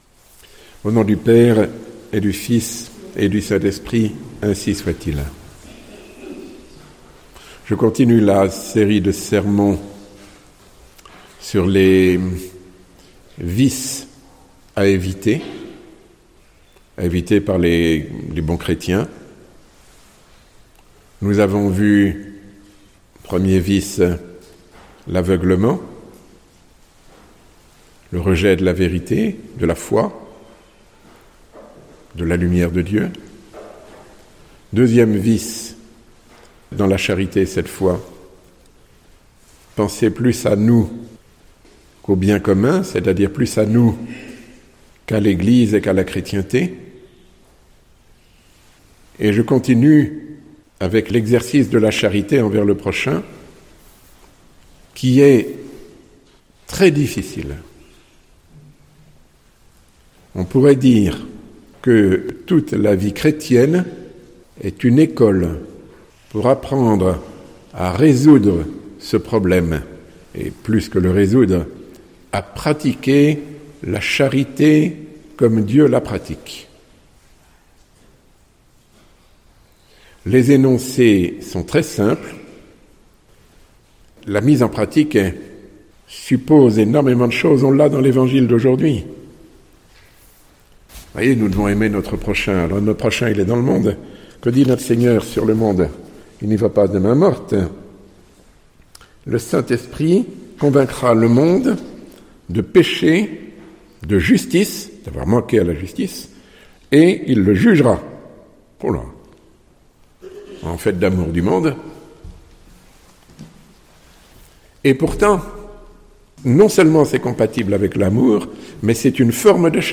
Sermon donné à La Villeneuve, sur le vif, un peu long, mais cela m’étonnerait que ne l’écoutiez pas jusqu’au bout.